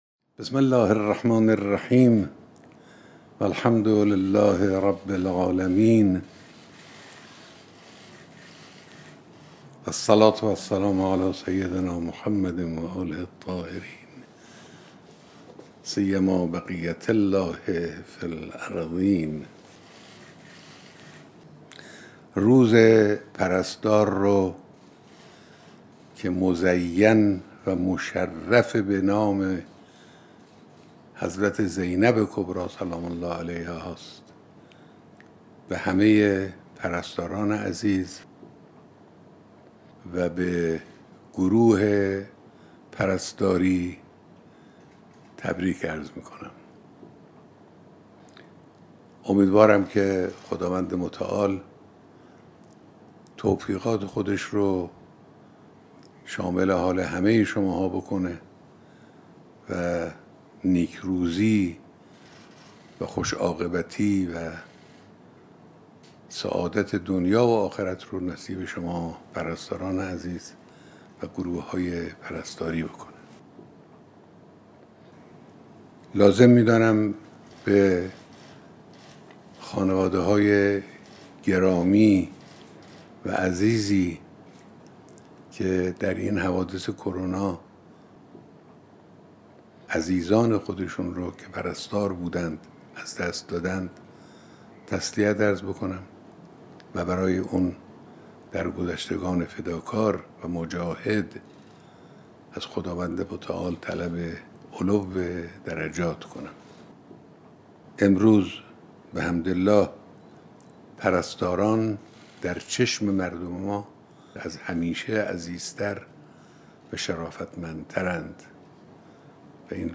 سخنرانی تلویزیونی به مناسبت سالروز ولادت حضرت زینب(س) و روز پرستار